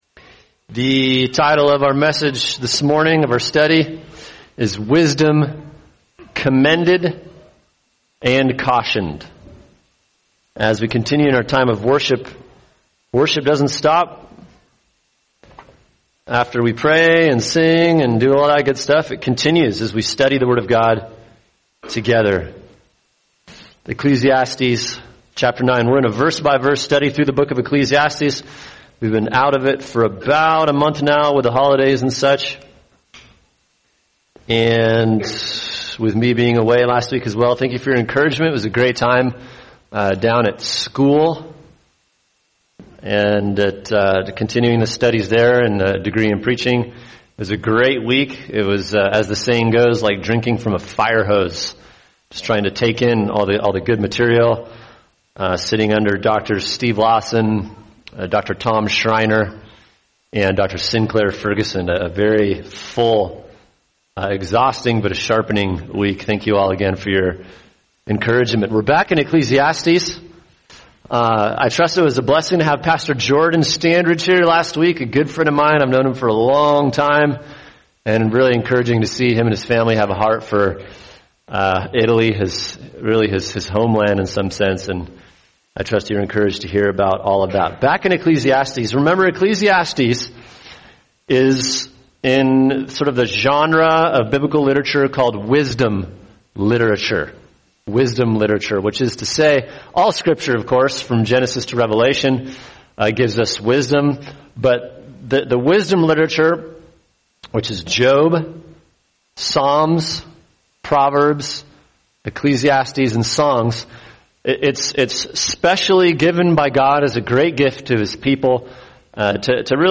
[sermon] Ecclesiastes 9:13-10:20 – Living Well In A World That Isn’t – Wisdom Commended & Cautioned | Cornerstone Church - Jackson Hole